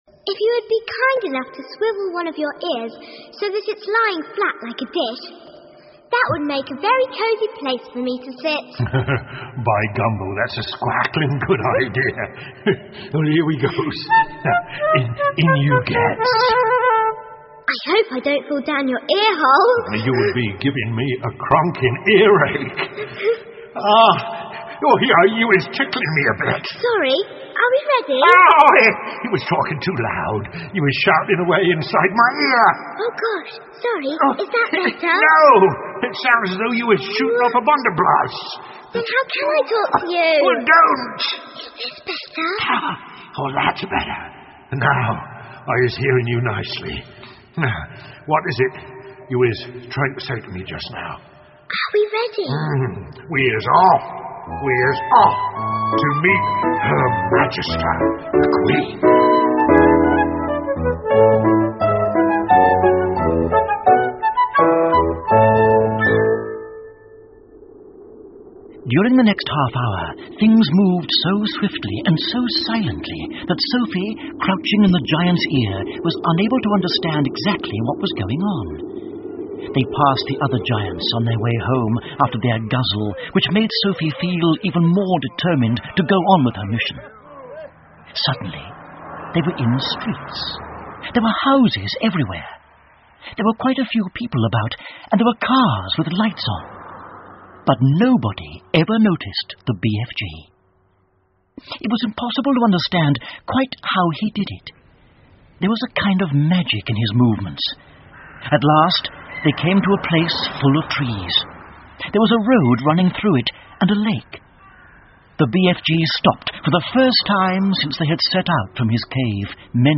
The BFG 好心眼儿巨人 儿童广播剧 12 听力文件下载—在线英语听力室